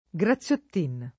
Graziottin [ g ra ZZL ott & n ] cogn.